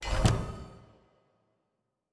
pgs/Assets/Audio/Fantasy Interface Sounds/Special Click 12.wav at master
Special Click 12.wav